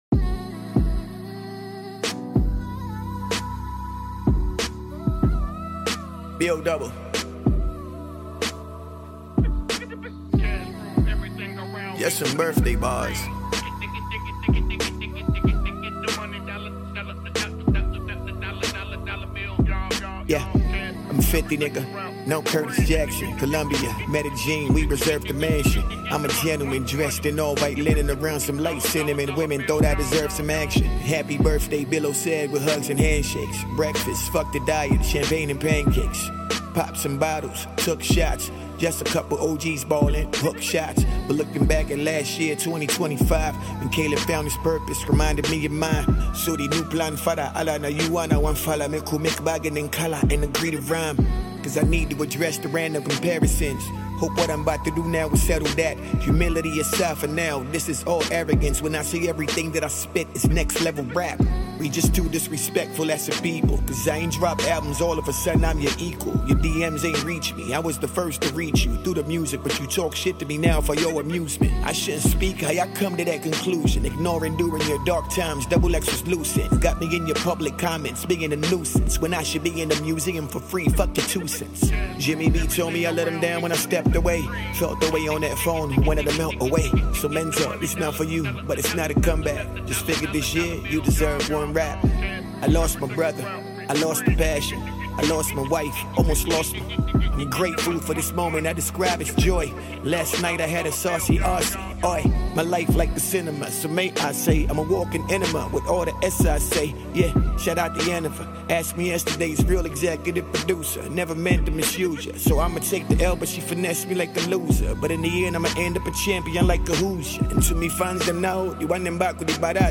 With a hardcore and dope beat